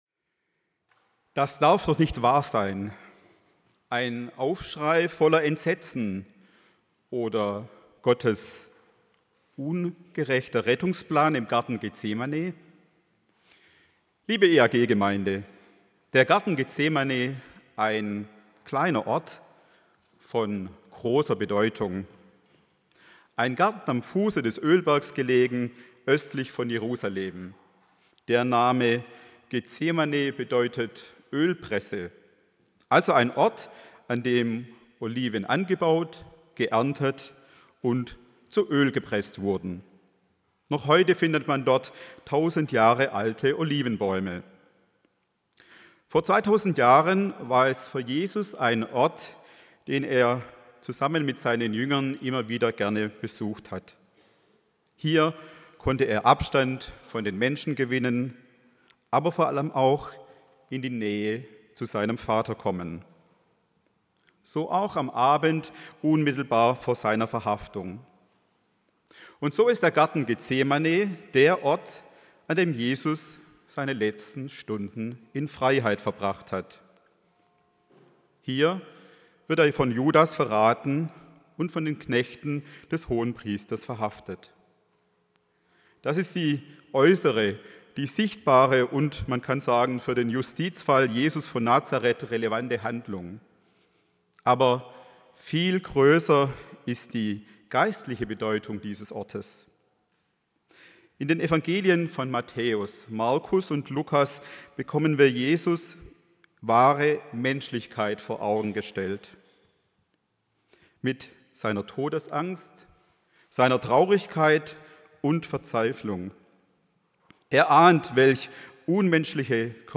Teil 2 vor 2 Wochen 29.77 MB Podcast Podcaster eAg PredigtCast Predigten aus einANDERERGottesdienst Religion & Spiritualität Folgen 0 Podcast aneignen Beschreibung vor 2 Wochen Mehr Weitere Episoden Was ist Wahrheit?